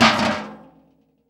garbage_can.R.wav